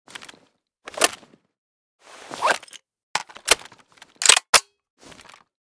wa2000_reload_empty.ogg